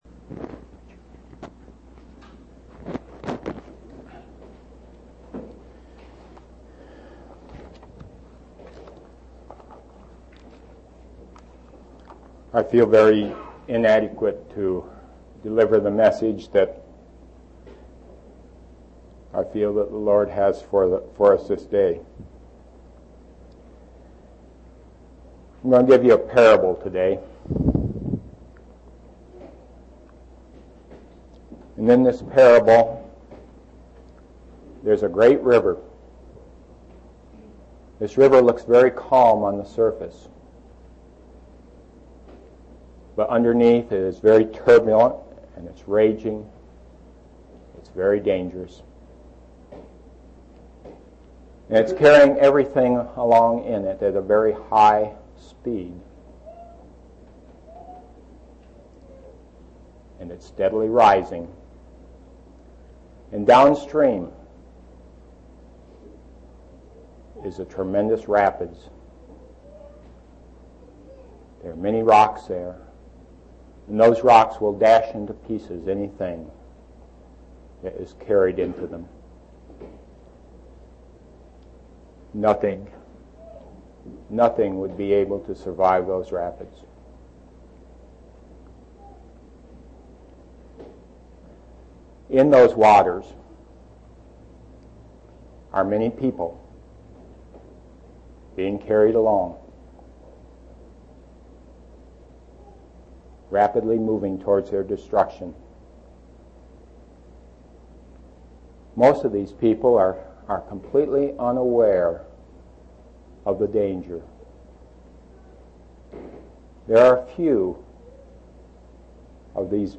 7/15/1990 Location: East Independence Local Event